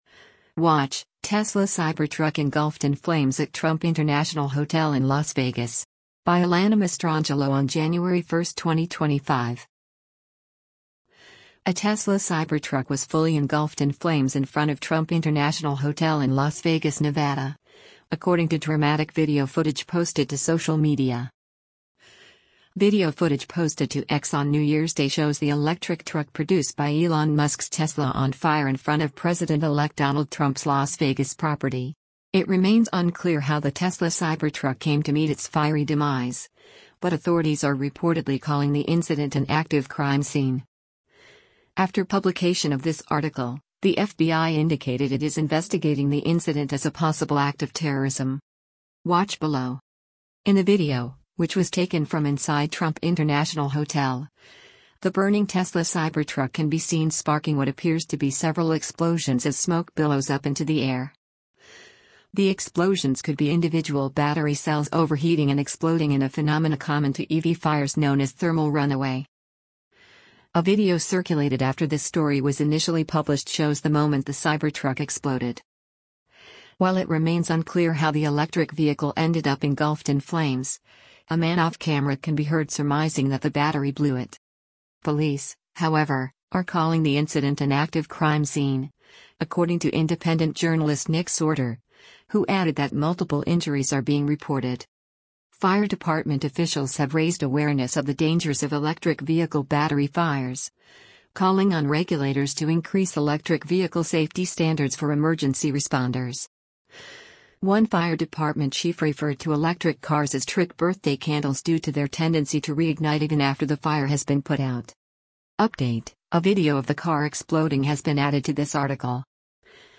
In the video, which was taken from inside Trump International Hotel, the burning Tesla Cybertruck can be seen sparking what appears to be several explosions as smoke billows up into the air.
While it remains unclear how the electric vehicle ended up engulfed in flames, a man off-camera can be heard surmising that “the battery blew it.”